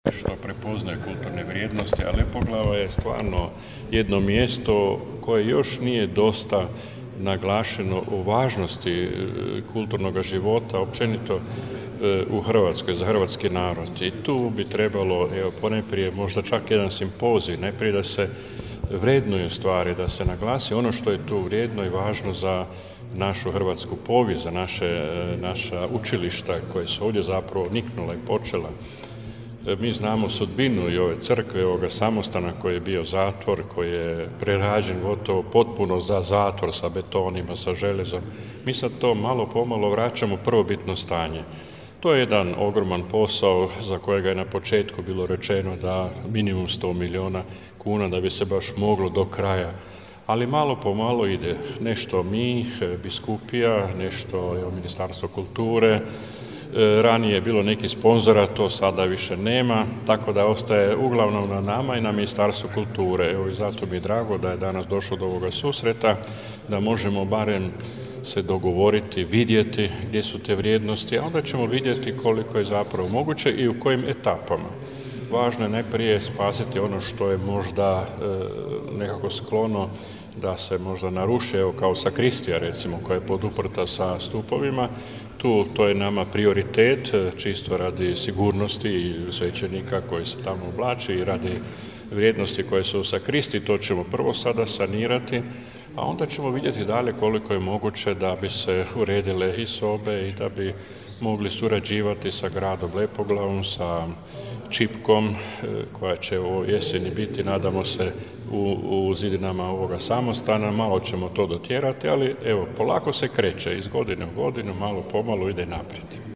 Izjava biskupa msgr. Josipa Mrzljaka